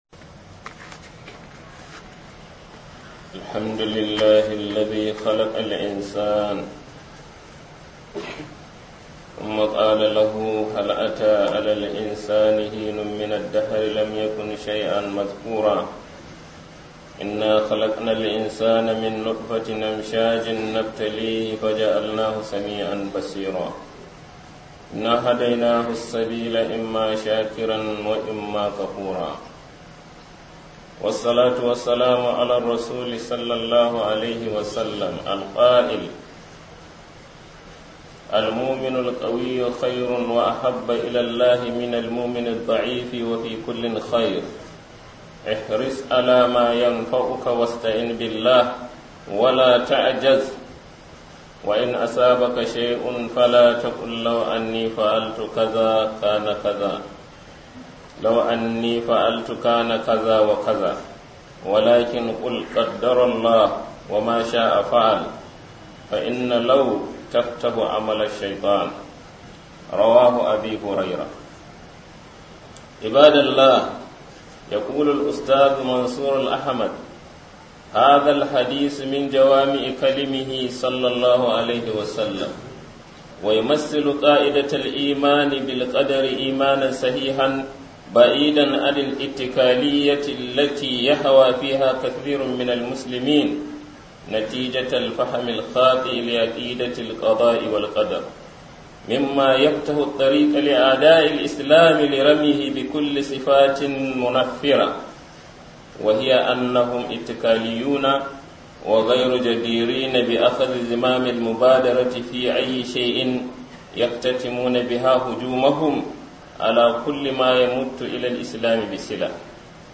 KHUDBAH JUMA-A